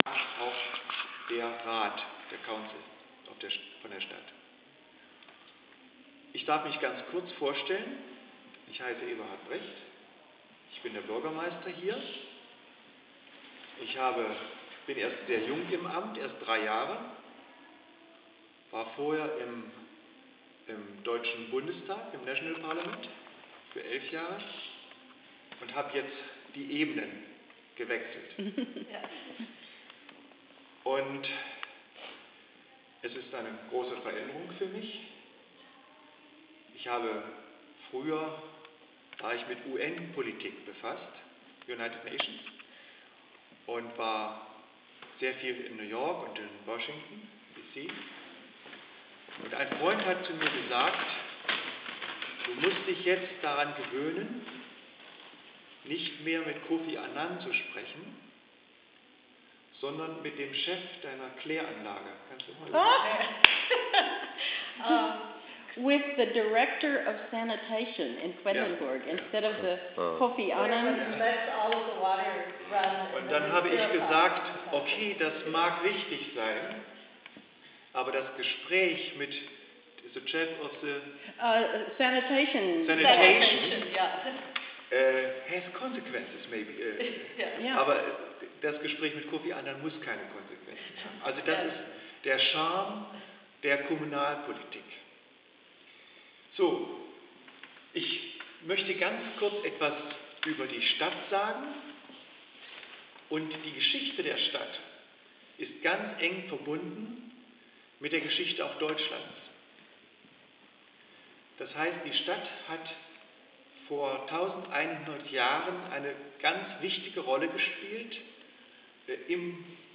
Der Bürgermeister über die Geschichte von Quedlinburg / The Mayor About the History of Quedlinburg